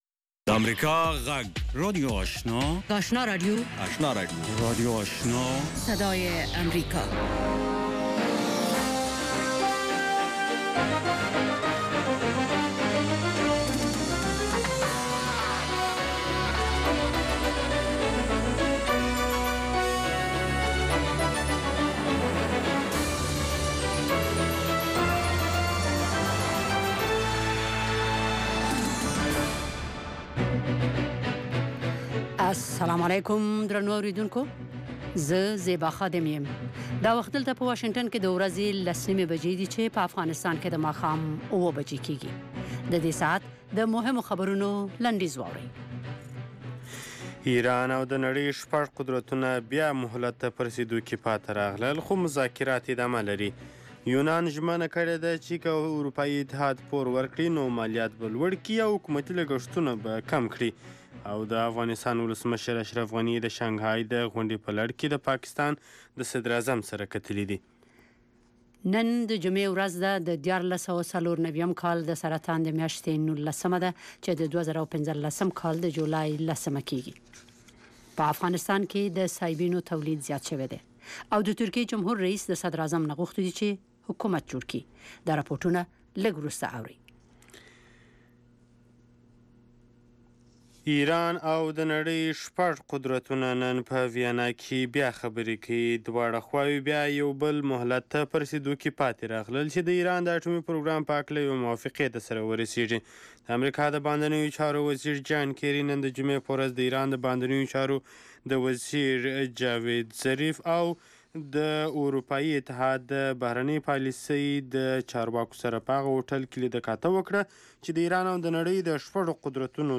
ماښامنۍ خبري خپرونه
په دې نیم ساعته خپرونه کې د افغانستان او نړۍ تازه خبرونه، مهم رپوټونه، مطبوعاتو ته کتنه او مرکې شاملې دي.